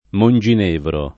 vai all'elenco alfabetico delle voci ingrandisci il carattere 100% rimpicciolisci il carattere stampa invia tramite posta elettronica codividi su Facebook Monginevro [ mon J in % vro ] o Monginevra [ mon J in % vra ] top. m. (Fr.)